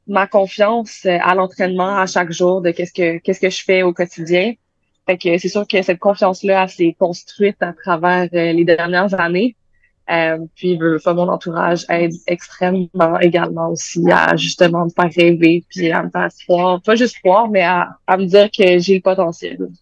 Quelques heures après sa victoire, elle a confié à l’émission VIA l’été qu’elle était bien heureuse de sa performance.